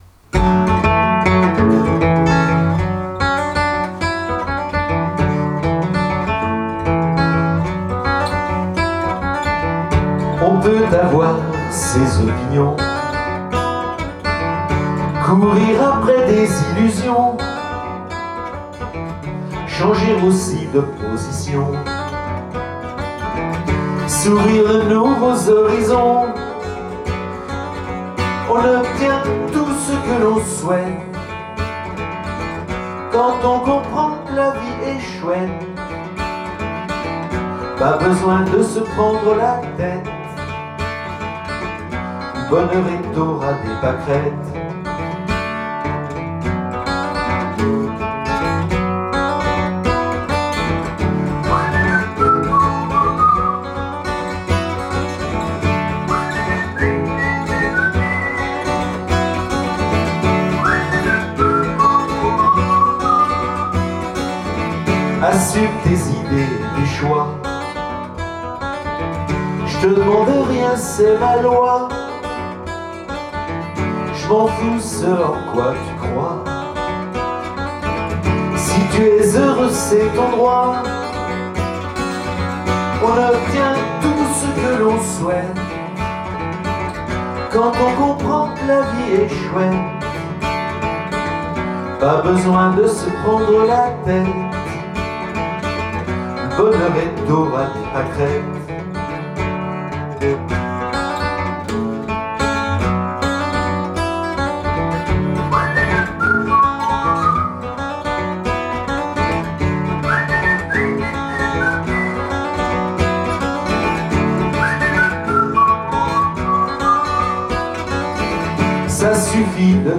et les appuis des danseurs pour un quickstep joyeux et sautillant.